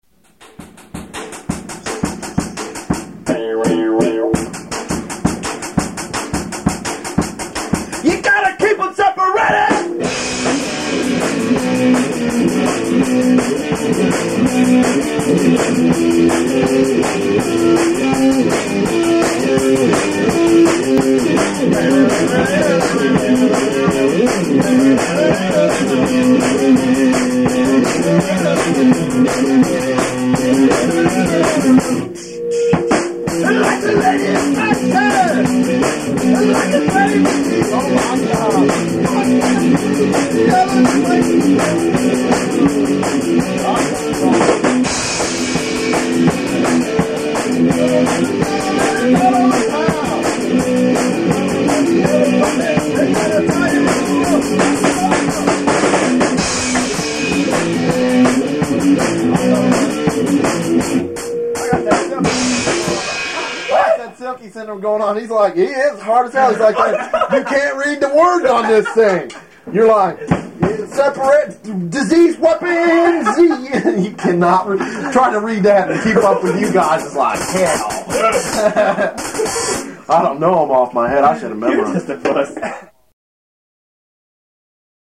Someone who can sing!